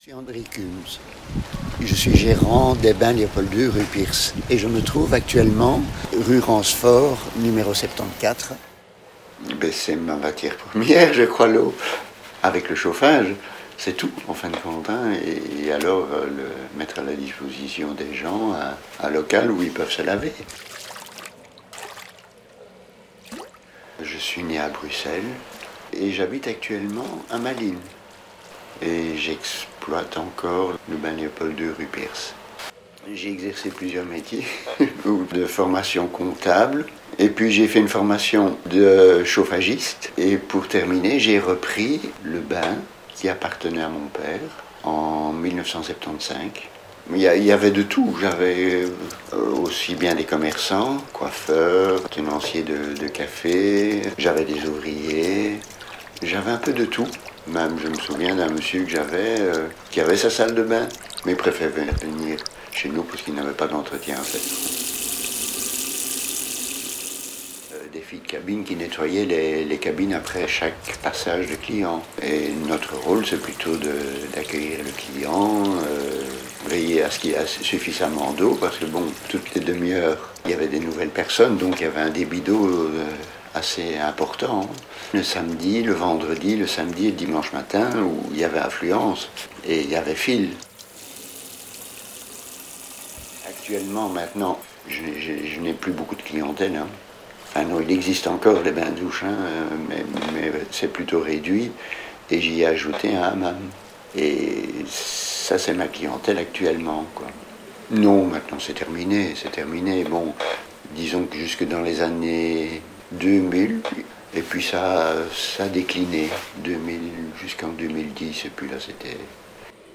Vous écoutez un podcast réalisé par La Fonderie et le Musée des Égouts, dans le cadre d’une balade sonore en autonomie. Cette balade sonore est un voyage dans les coulisses des métiers de l’eau à Bruxelles.